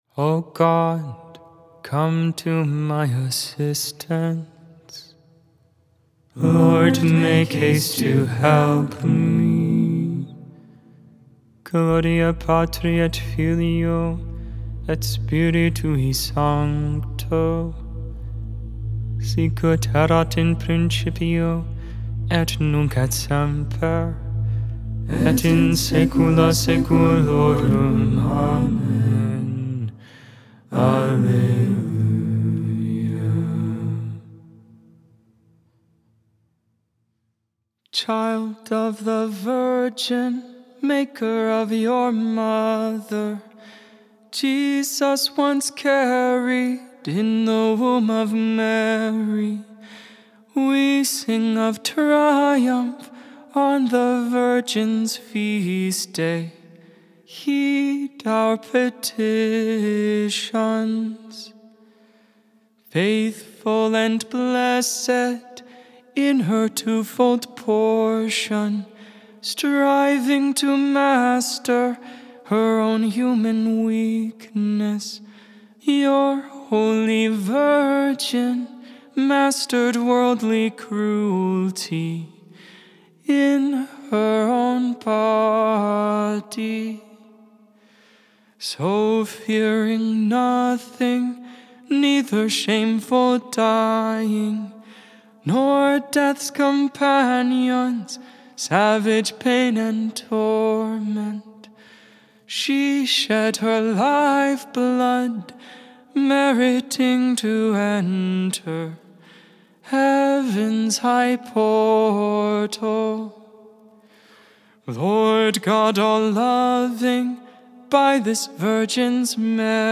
Vespers